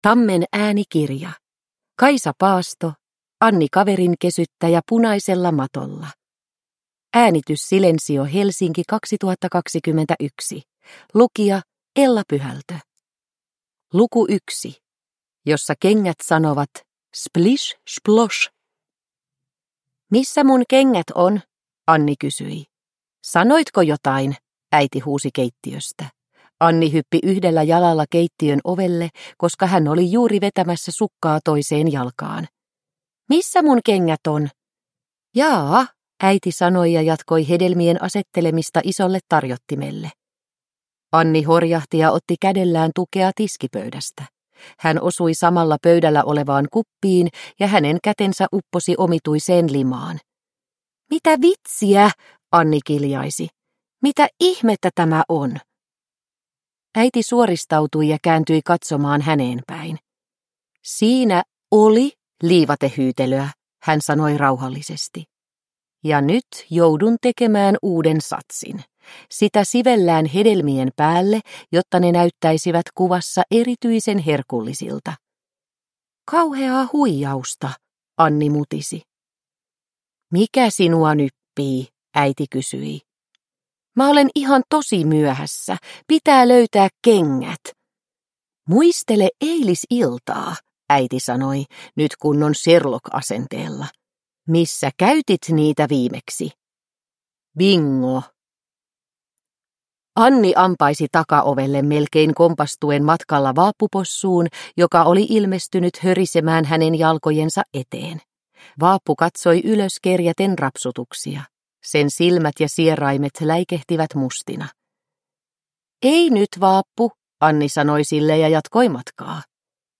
Anni kaverinkesyttäjä punaisella matolla – Ljudbok – Laddas ner